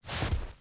tbd-station-14/Resources/Audio/Effects/Footsteps/snowstep1.ogg at 0bbe335a3aec216e55e901b9d043de8b0d0c4db1
snowstep1.ogg